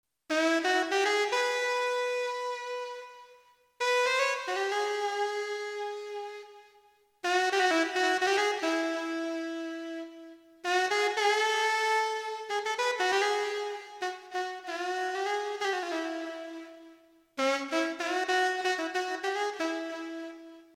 Vl-инструмент носит название Lite Alto.
М: Перебор с реверберацей совершенно не даёт понять — что же за тембры у YMF724 и PLG100-VL?